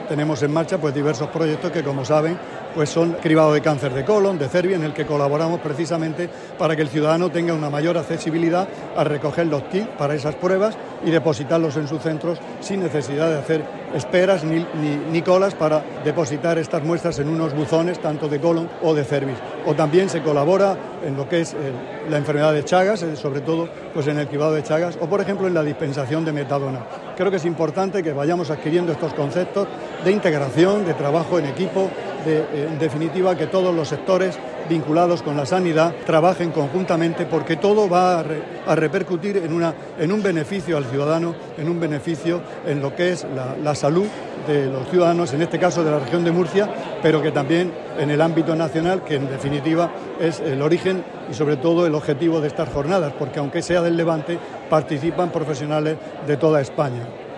Declaraciones del consejero de Salud, Juan José Pedreño, sobre la colaboración con el Colegio de Farmacéuticos, en la Jornada Farmacéutica del Levante
El consejero de Salud, Juan José Pedreño, en la inauguración de las II Jornadas del Levante Farmacéutico, que se celebran en Murcia.